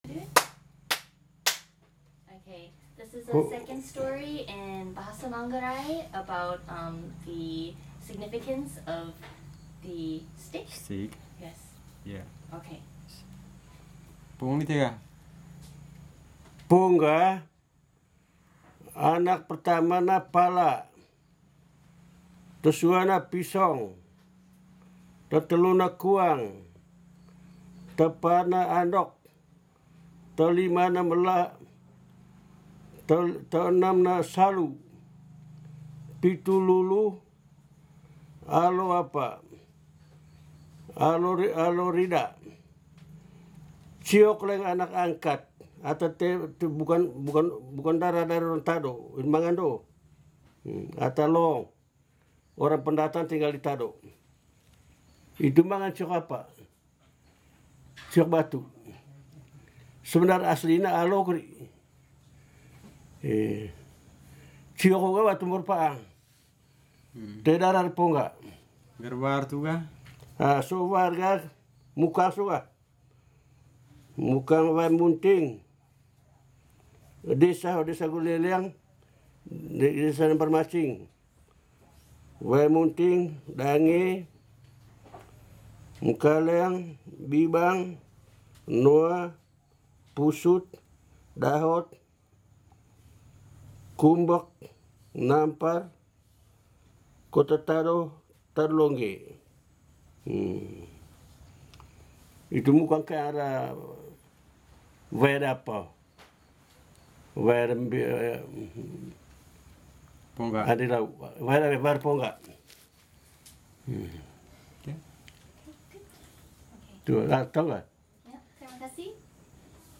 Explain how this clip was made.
Tado Village, Flores, East Nusa Tenggara, Indonesia (8°39'16.2"S 120°04'18.7"E).